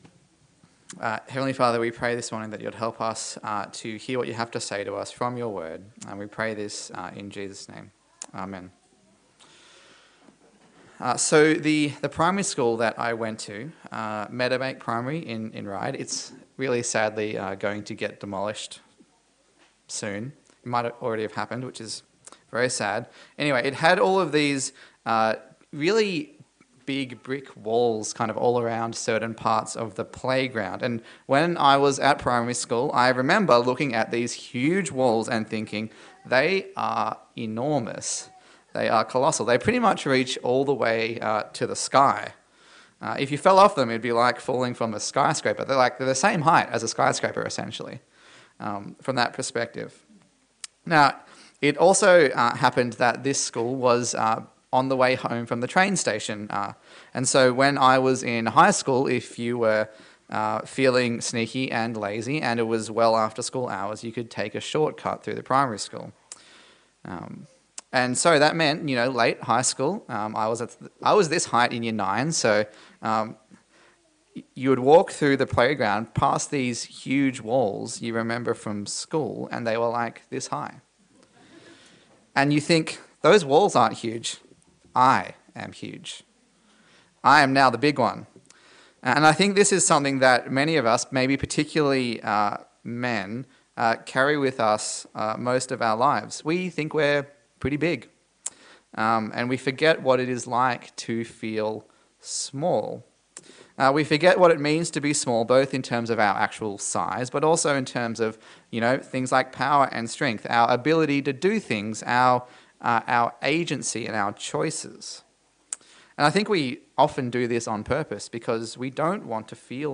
Luke Passage: Luke 8:22-39 Service Type: Sunday Service